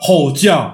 Play, download and share 吼叫 original sound button!!!!
hou-jiao.mp3